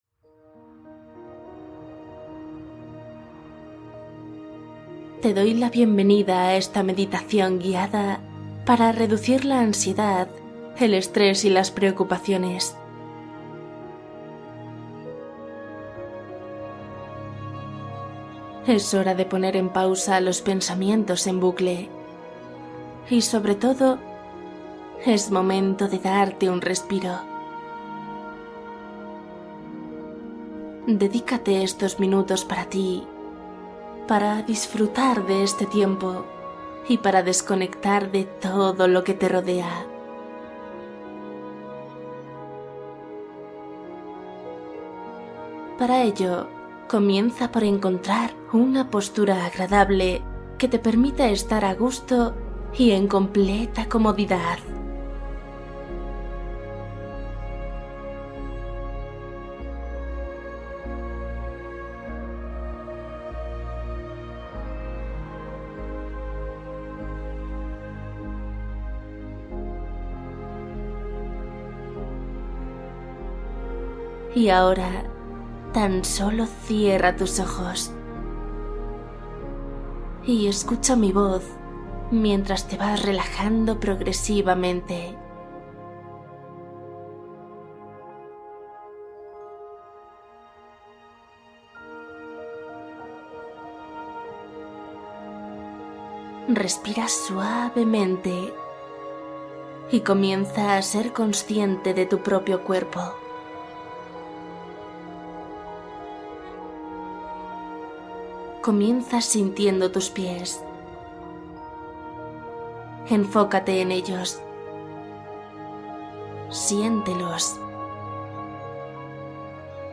Meditación para ansiedad, estrés y preocupaciones antes de dormir